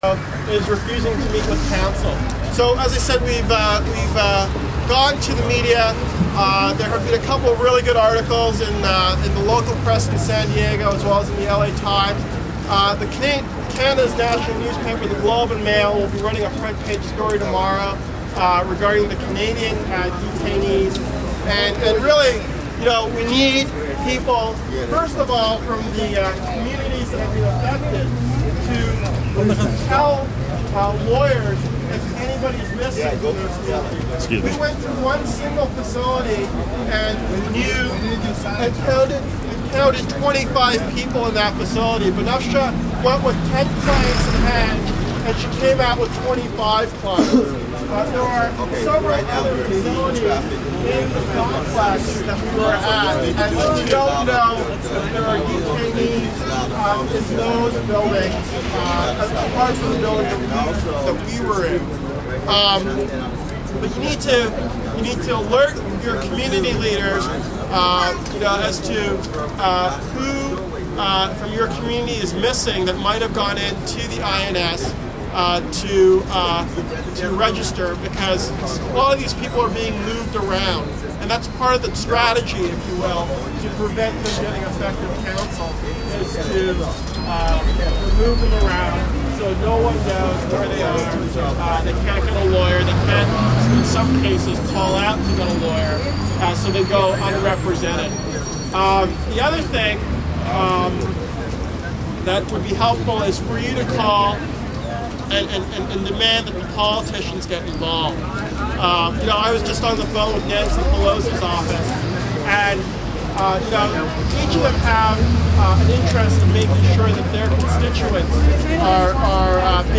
speech4.mp3